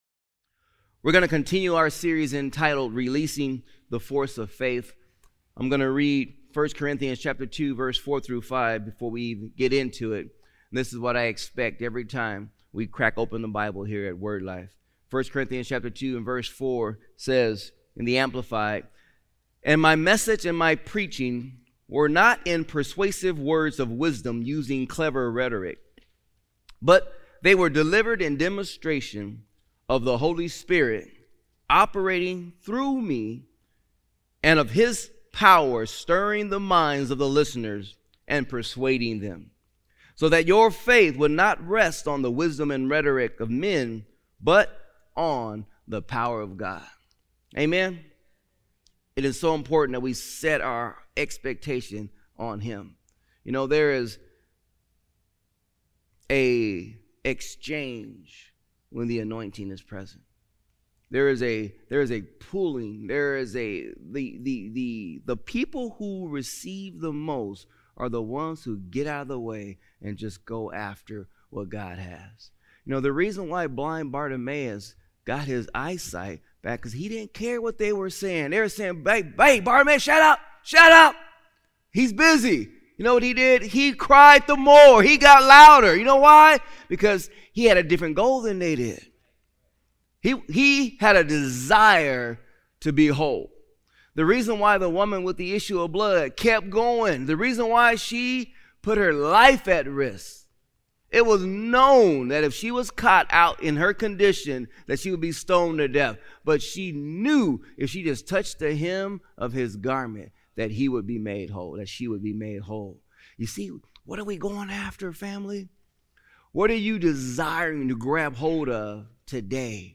Sermons | Word Life Church